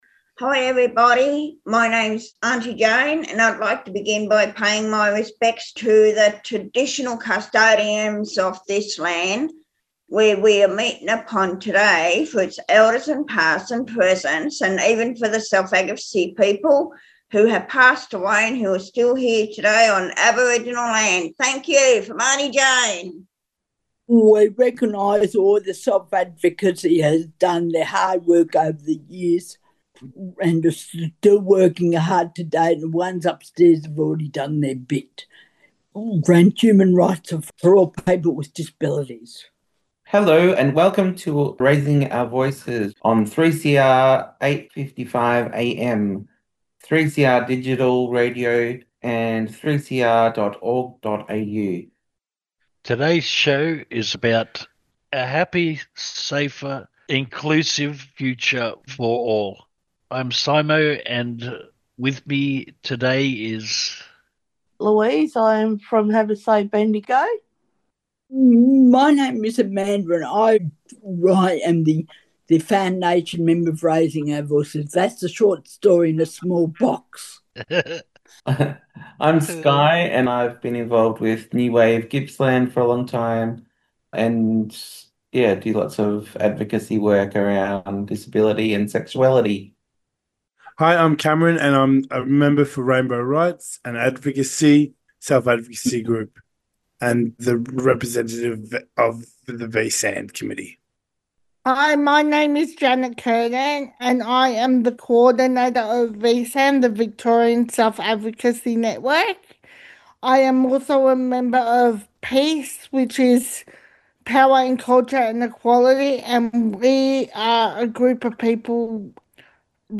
Tweet Raising Our Voices Wednesday 6:00pm to 6:30pm Produced and presented by people with disabilities covering issues such as housing, discrimination, difficulties with public transport, self-advocacy and other related issues.